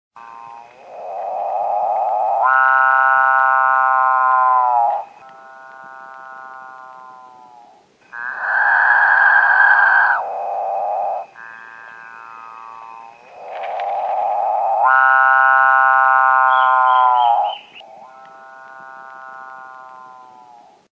socó-boi?
Todos sons do centro da cidade de Ubatuba.